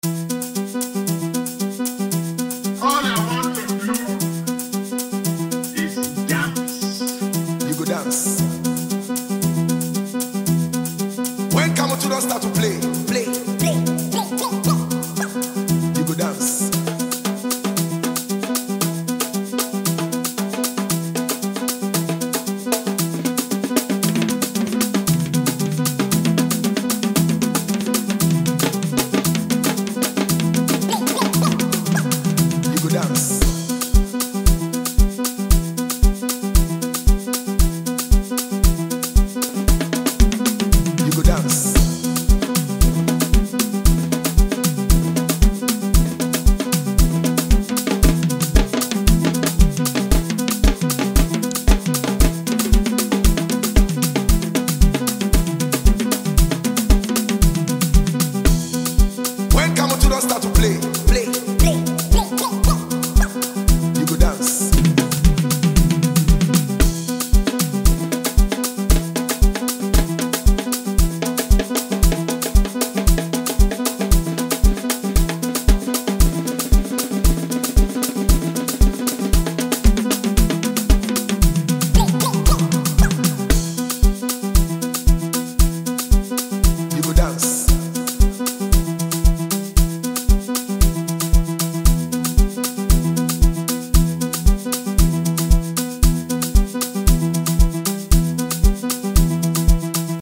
dance track